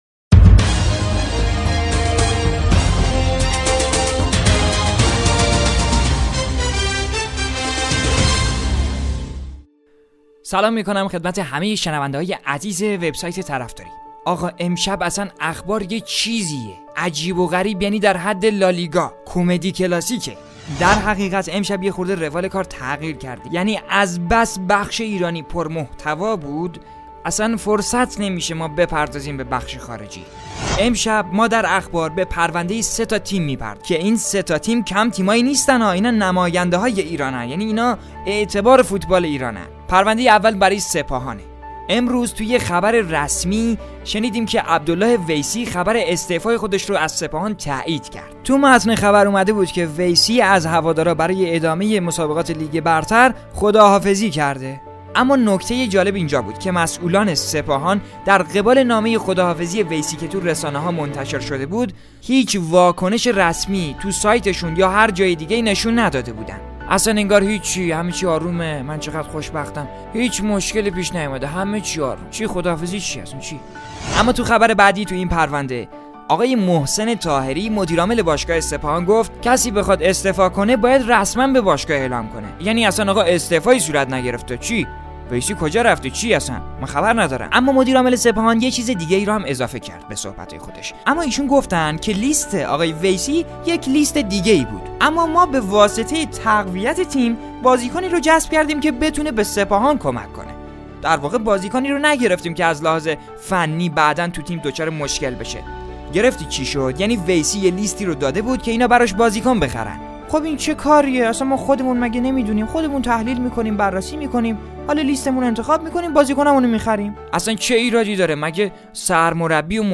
پادکست خبری با چاشنی طنز؛ بررسی پرونده‌های جمعه سیاه فوتبال ایران